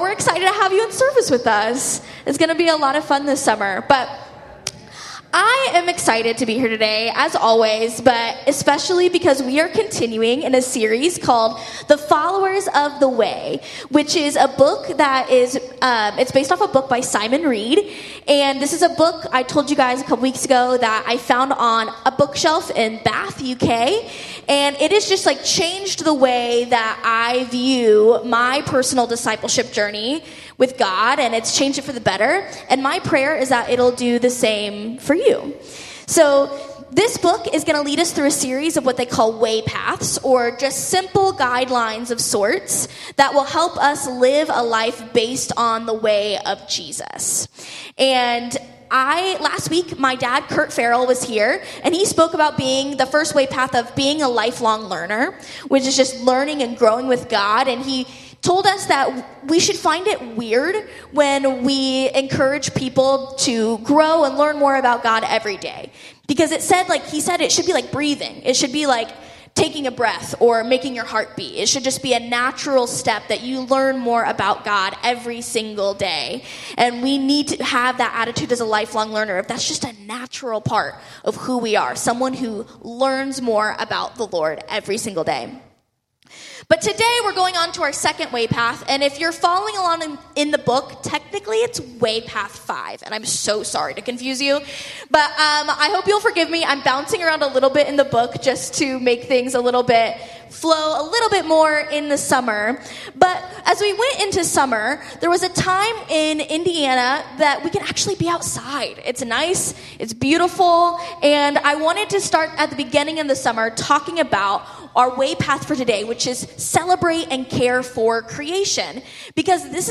Meridian Church of God weekly message.